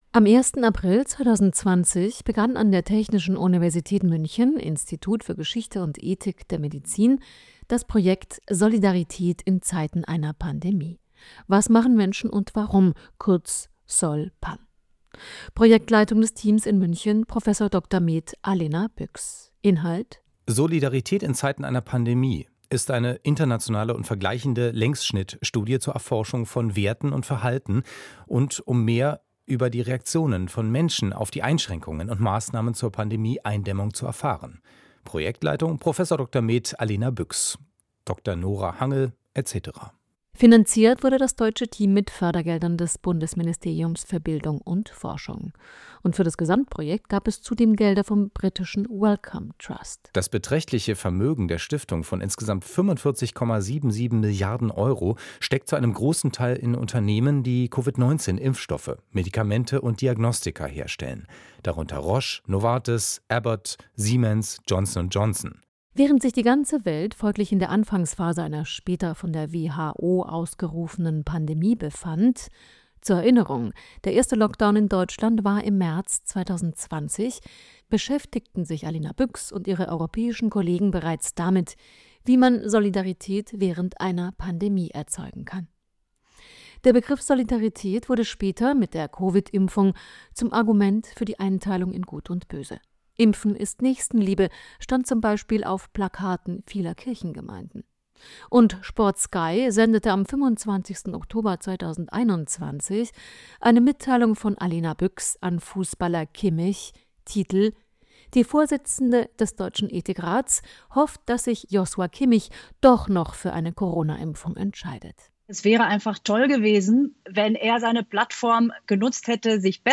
im Gespräch mit der Wissenschaftsjournalistin